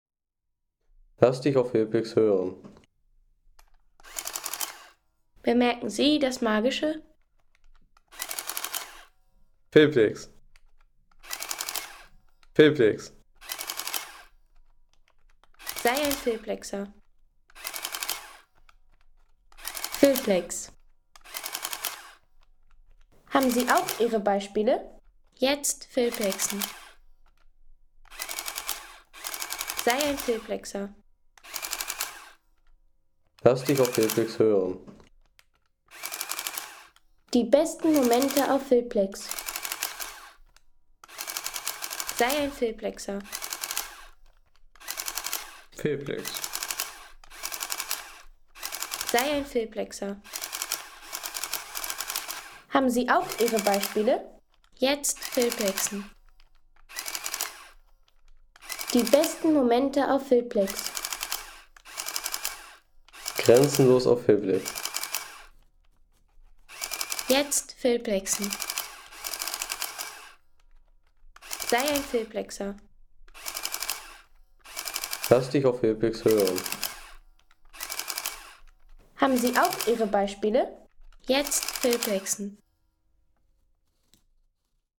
Sharp EL-1625H Taschenrechner